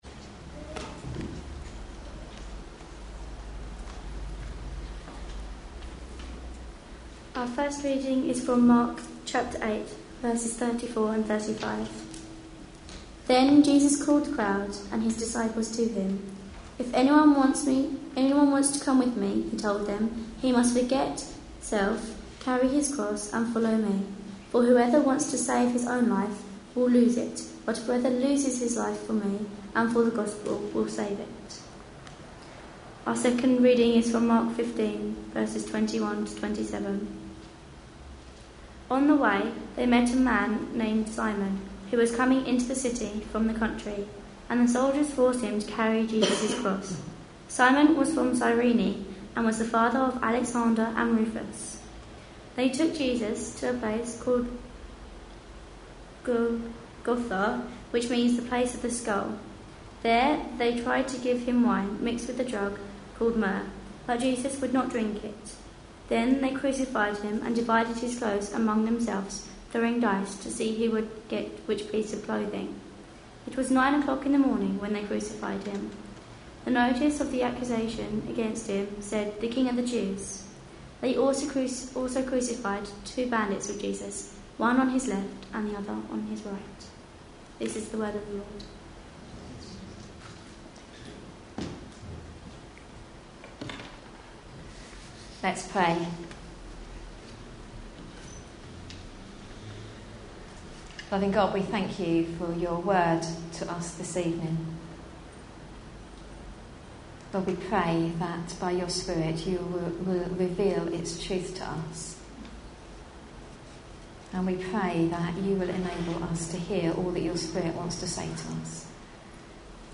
A sermon preached on 17th March, 2013, as part of our Passion Profiles and Places -- Lent 2013. series.